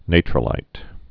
(nātrə-līt)